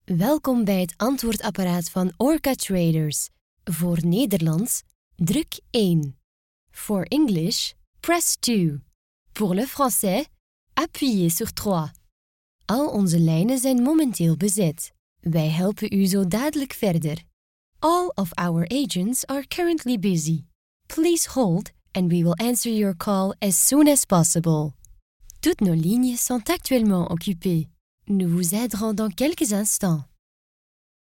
Commercial, Young, Natural, Versatile, Friendly
Telephony